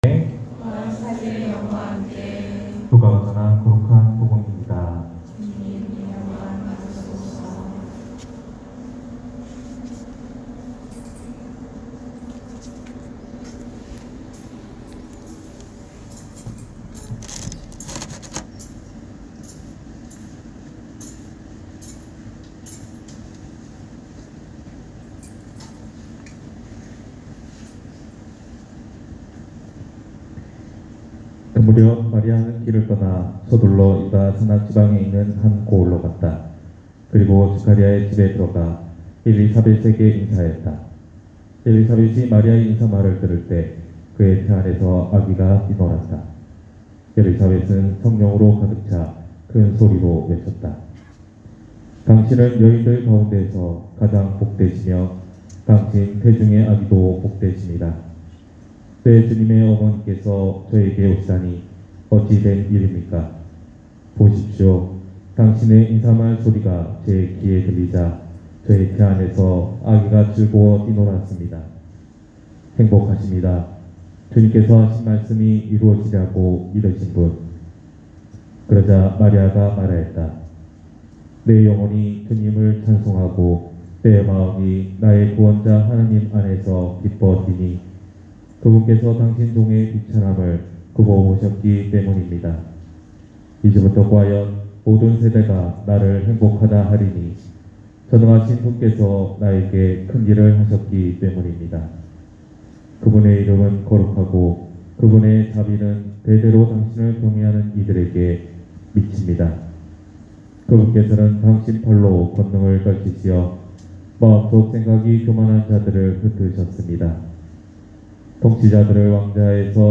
250815 성모승천대축일 신부님강론말씀